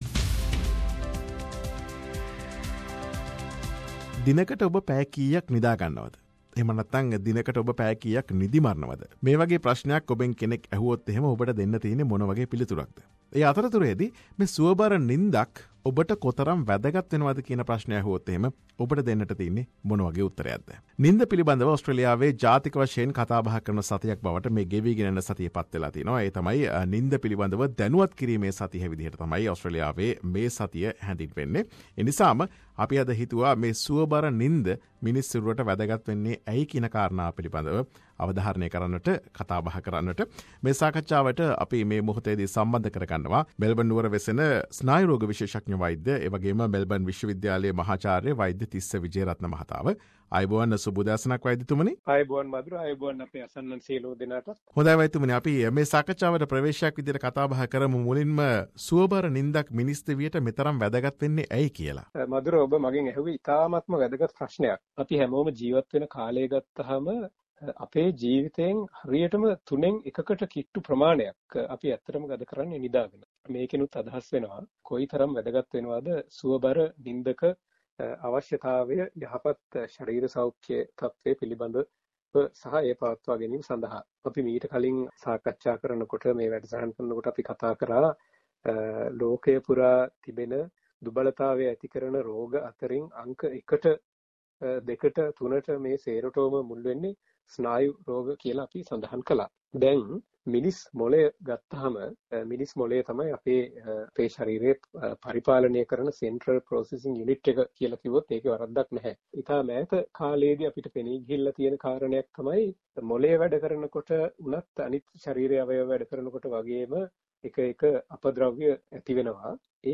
SBS සිංහල වැඩසටහන සිදුකළ සාකච්ඡාව.